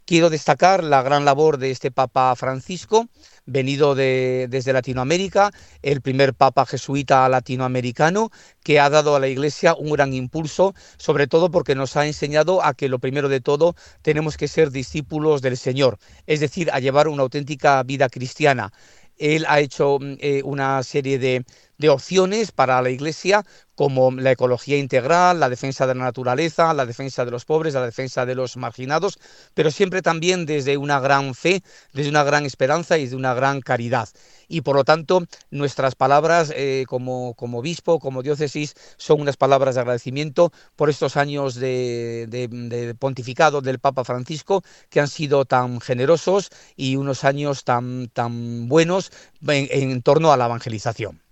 OBISPO-recordarmos-la-gran-labor-del-Papa.mp3